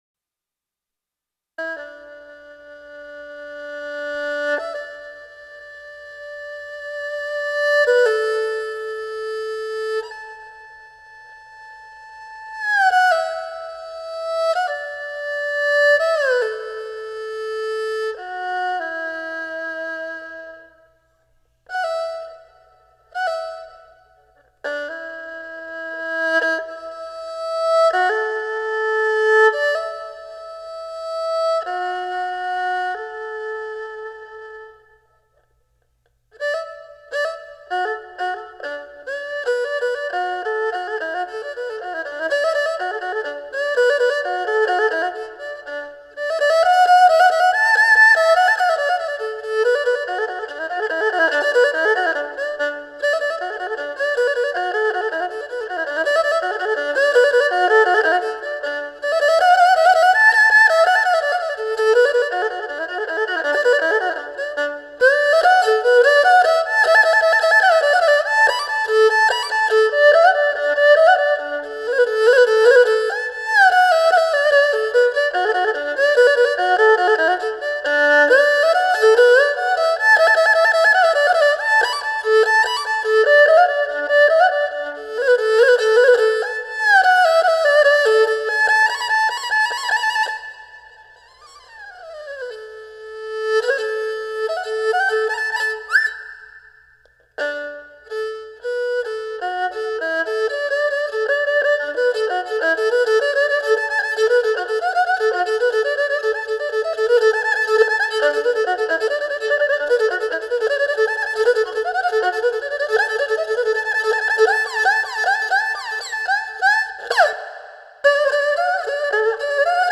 东方音乐